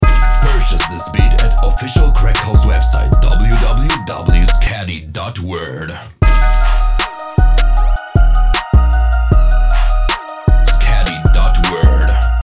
REAL TRAP